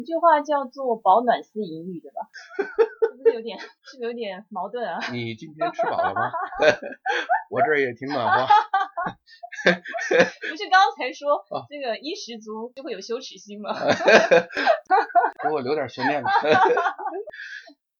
上面三段就是被美女主播无情删除的。
Big_laugh_but_wasted_for_poor_sound_quality.mp3